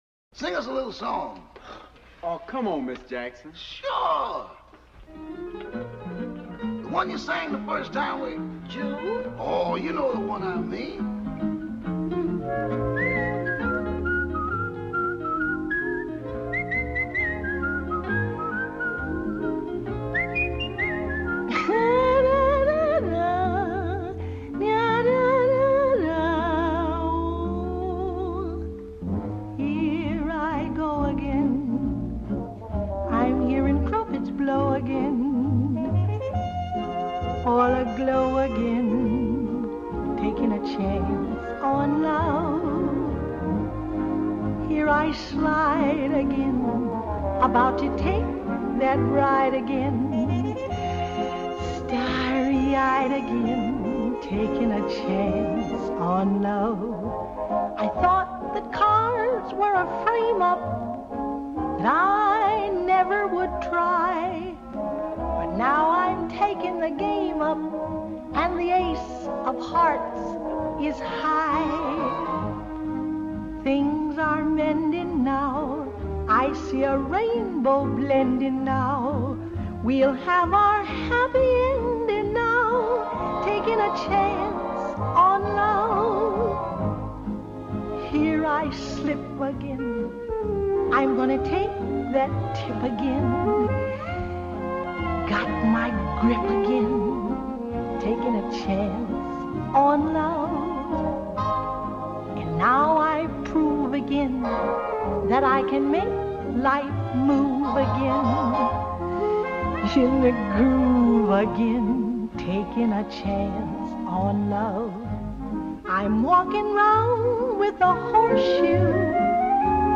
1940   Genre: Musical   Artist